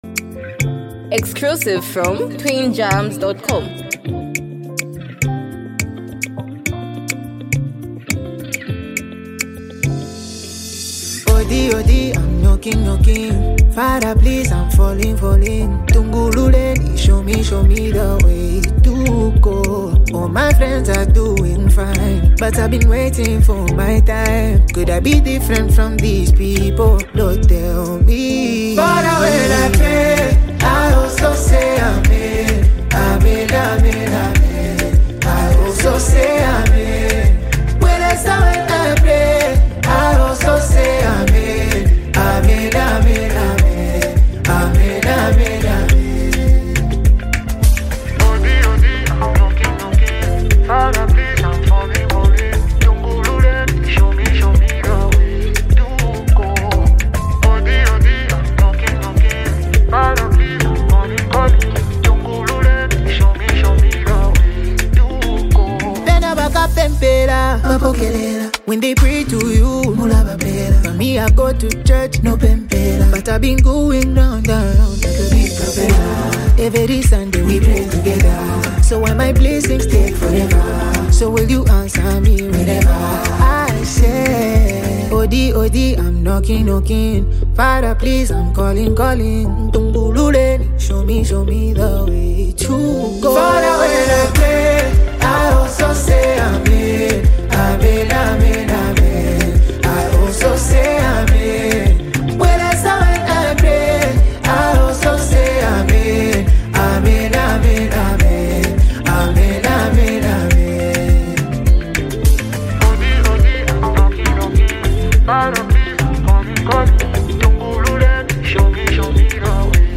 a reflective and spiritually grounded song